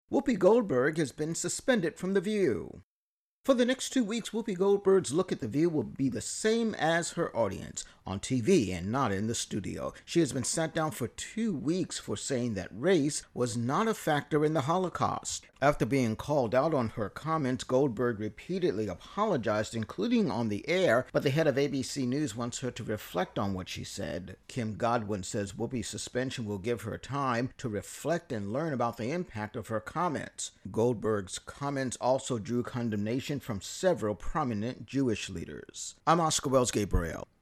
Intro+voicer on Whoopi Goldberg's suspension over Holocaust remarks